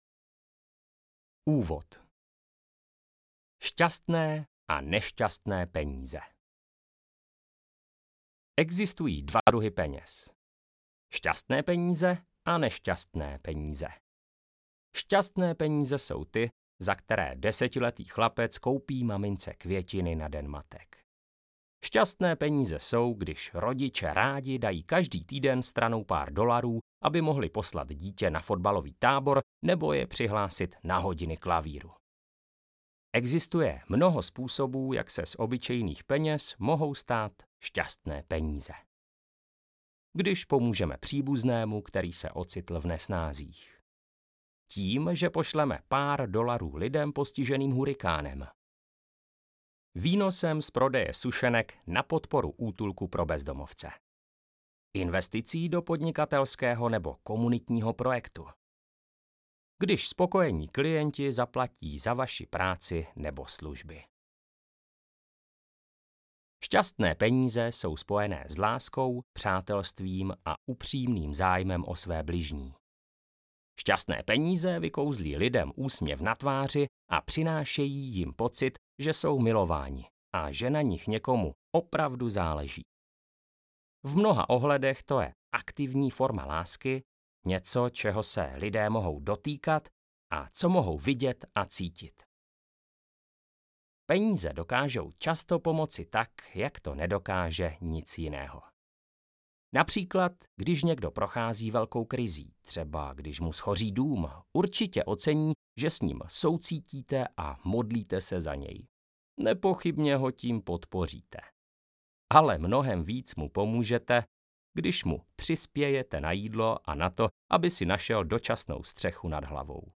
Audiokniha Šťastné peníze - Ken Honda | ProgresGuru